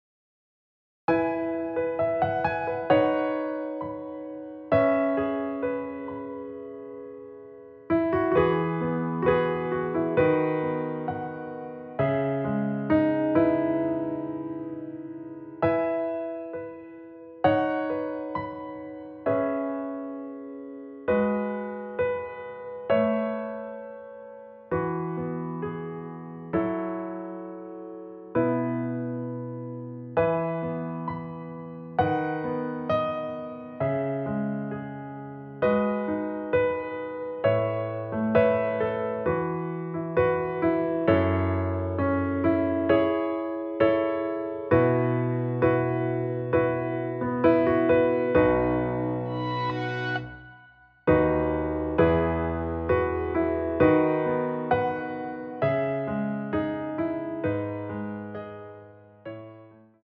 대부분의 남성분들이 부르실수 있는 키로 제작 하였습니다.
앞부분30초, 뒷부분30초씩 편집해서 올려 드리고 있습니다.
중간에 음이 끈어지고 다시 나오는 이유는